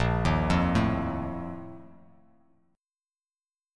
A#M7sus4 chord